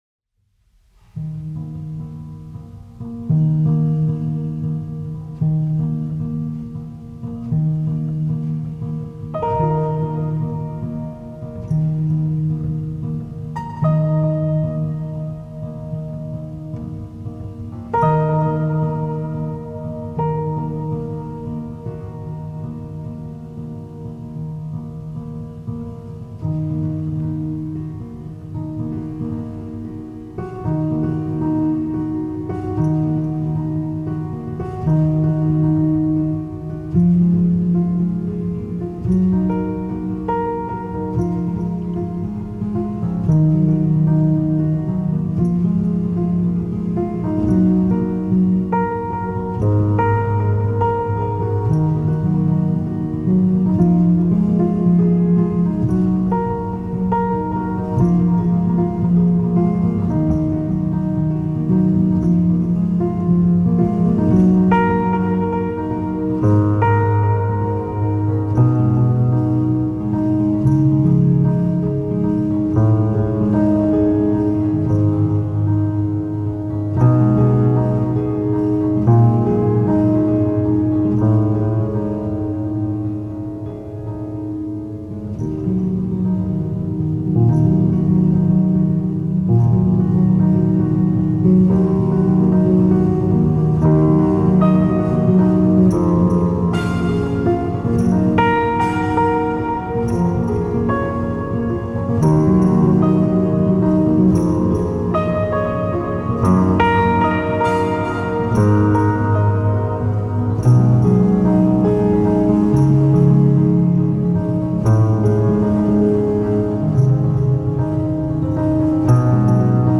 Für Piano komponiertes Solo-Stück.